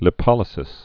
(lĭ-pŏlĭ-sĭs, lī-)